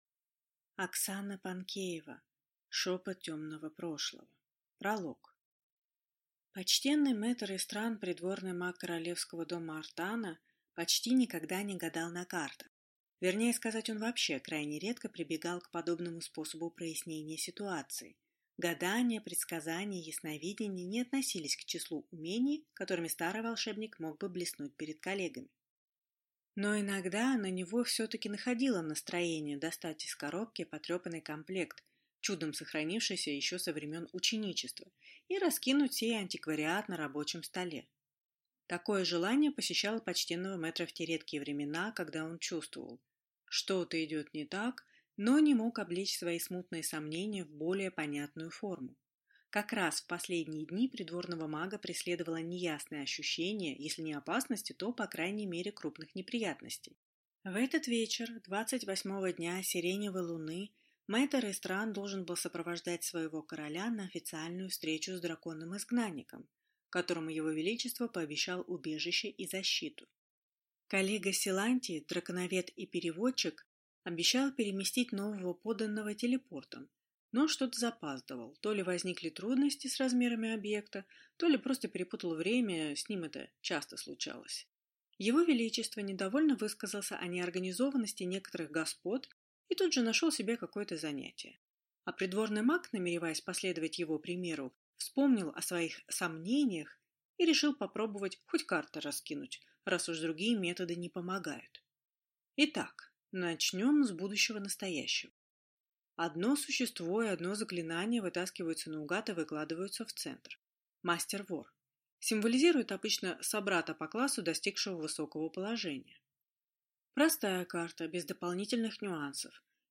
Аудиокнига Шепот Темного Прошлого | Библиотека аудиокниг